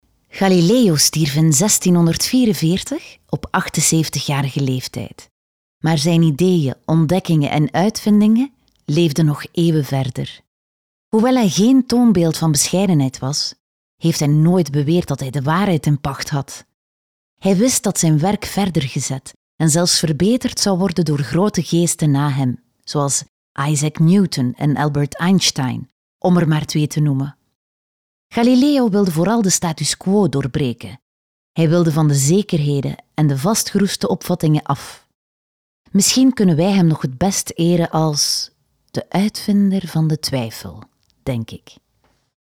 Natürlich, Warm, Sanft, Zugänglich, Freundlich
Erklärvideo